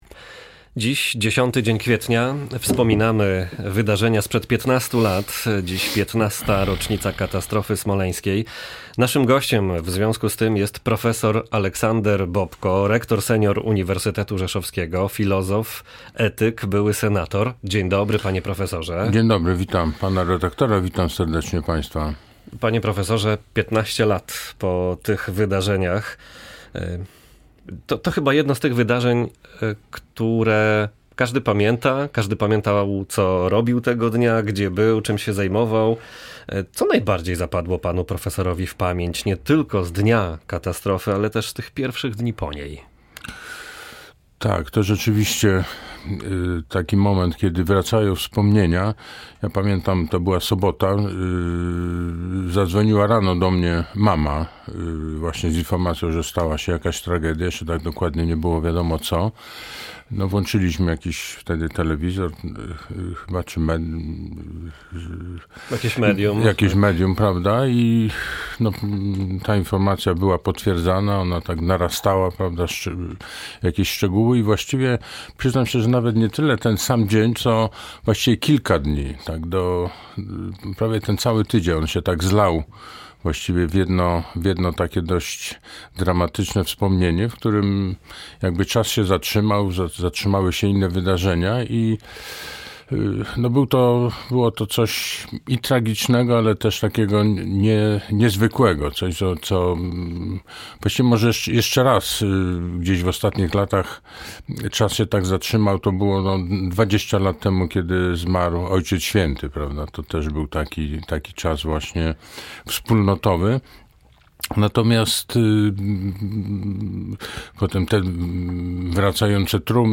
Mija 15 lat od katastrofy smoleńskiej, w której zginęło 96 członków polskiej delegacji. Prof. Aleksander Bobko, filozof, etyk, a także były senator na antenie Polskiego Radia Rzeszów stwierdził, że choć było to traumatyczne zdarzenie, które miało połączyć rodaków, podzieliło ich.